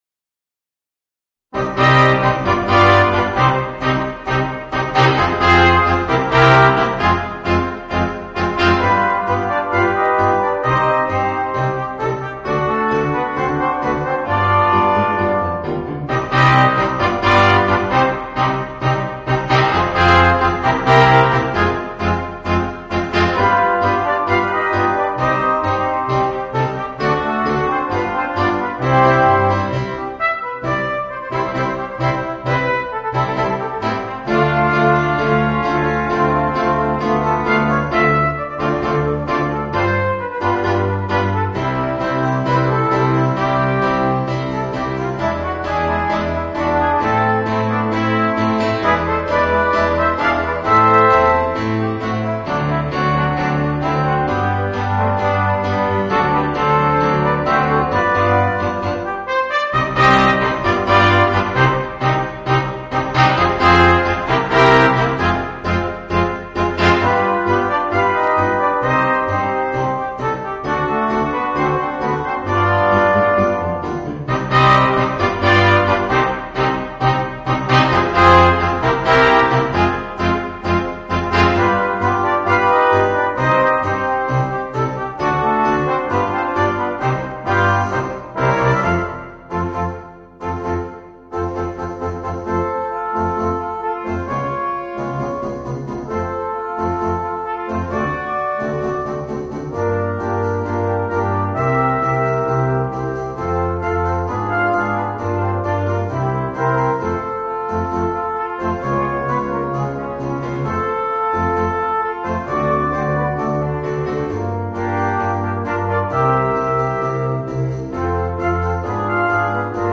Voicing: 3 Trumpets, Trombone and Tuba